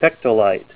Help on Name Pronunciation: Name Pronunciation: Pectolite + Pronunciation
Say PECTOLITE Help on Synonym: Synonym: ICSD 34945   Larimar - blue   PDF 33-1223